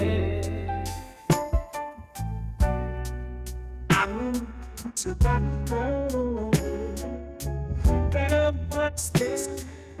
80s music-generation